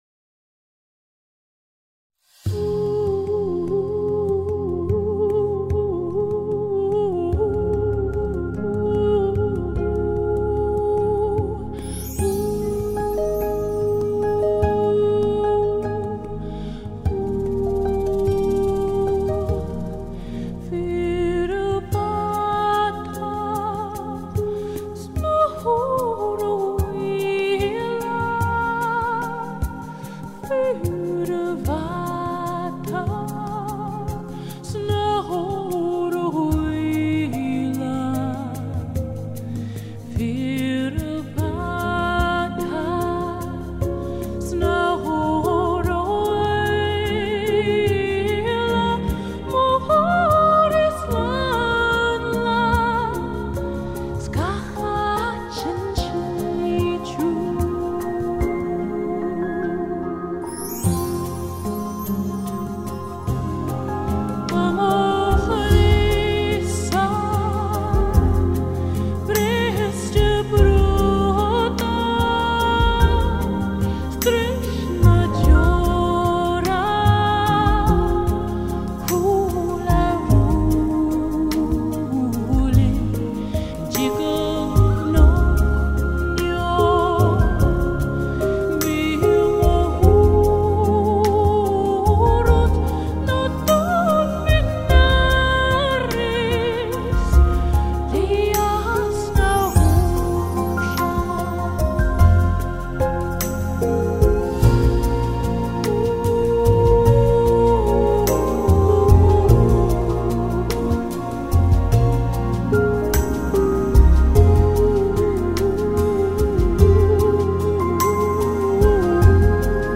Fear 'a Bhata (trad. Scottish
Fear+'a+Bhata+(bigger+accompaniment+version,+not+released).mp3